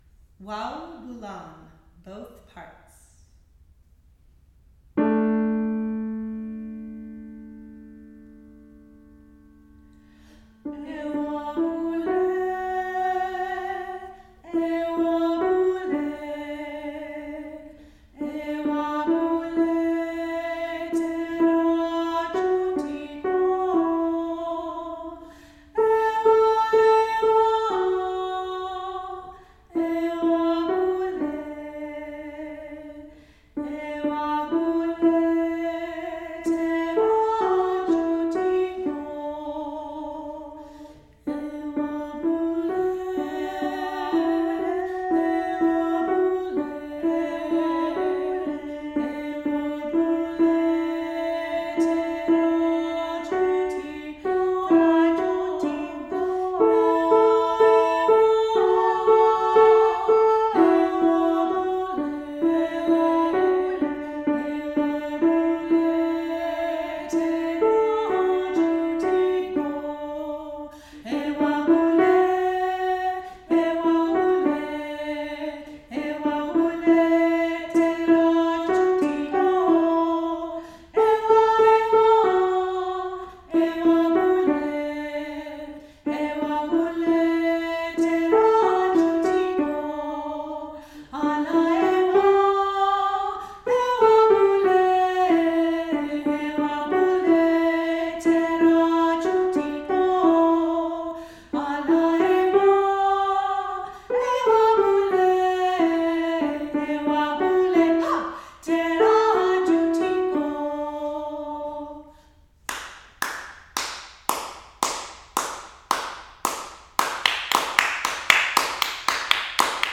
Practice Tracks